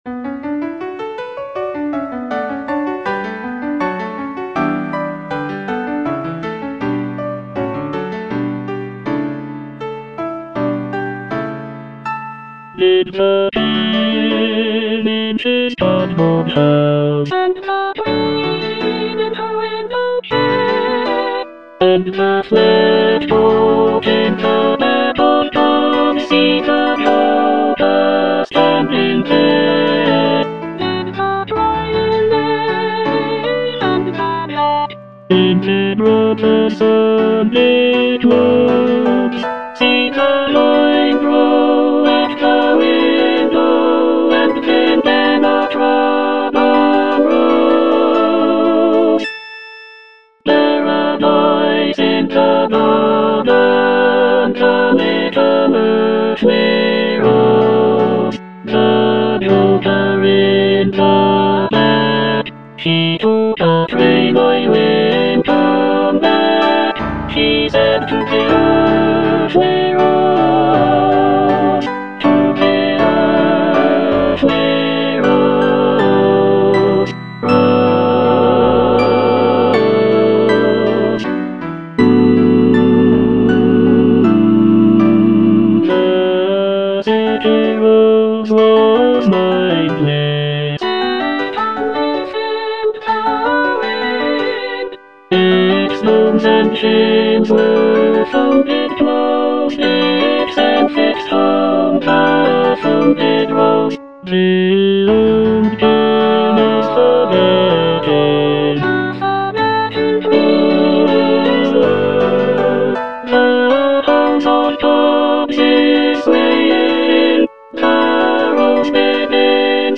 Tenor I (Emphasised voice and other voices)